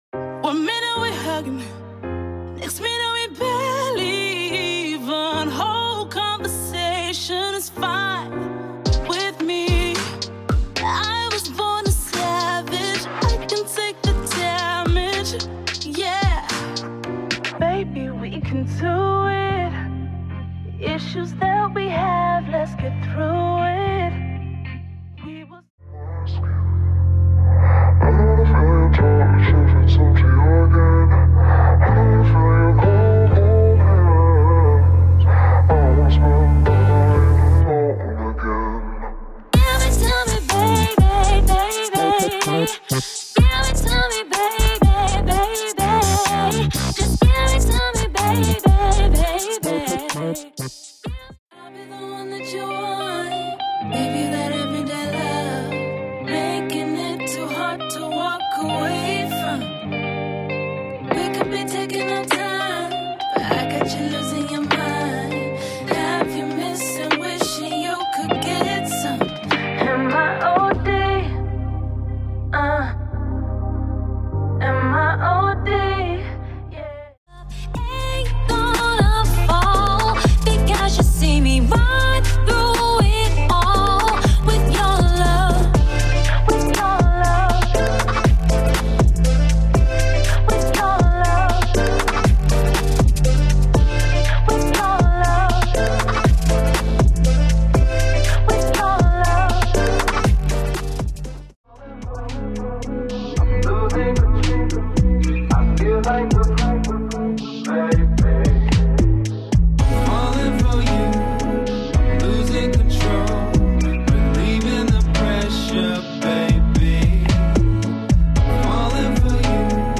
styl - pop/soft pop/dance